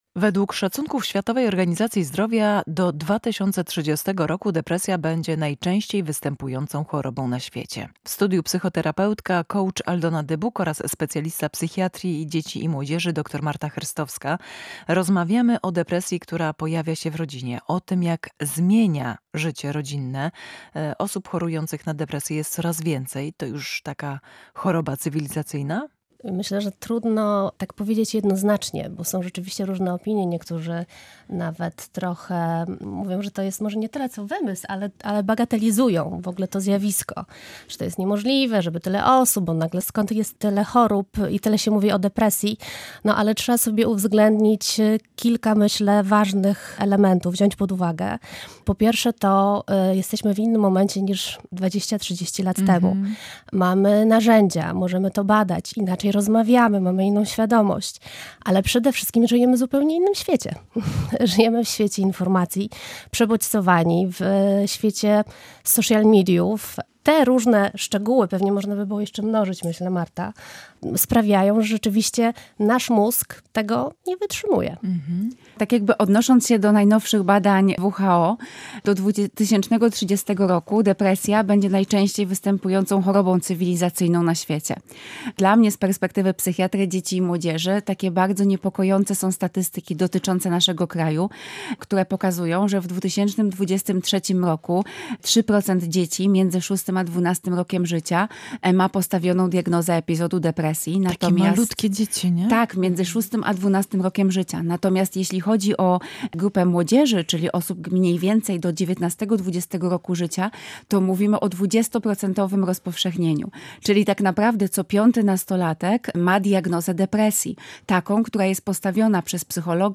Co zrobić, kiedy depresja wkracza do rodzinnego domu? Rozmowa z ekspertkami